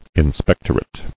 [in·spec·tor·ate]